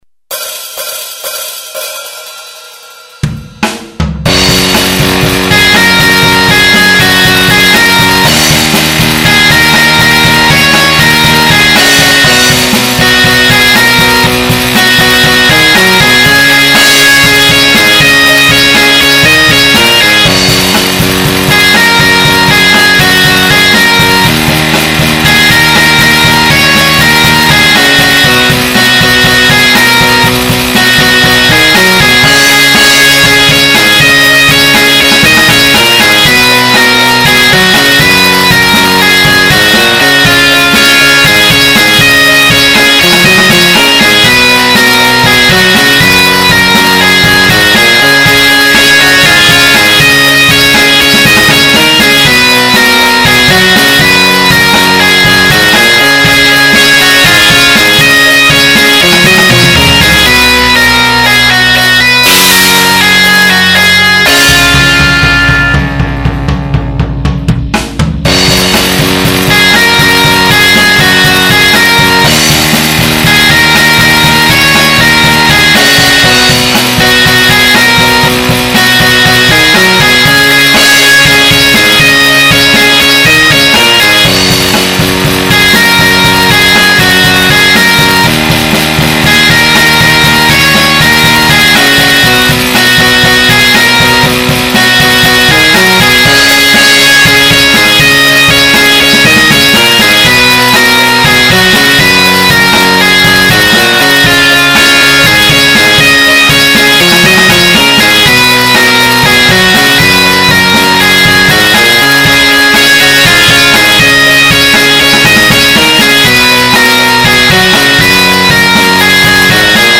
Playbacks instrumental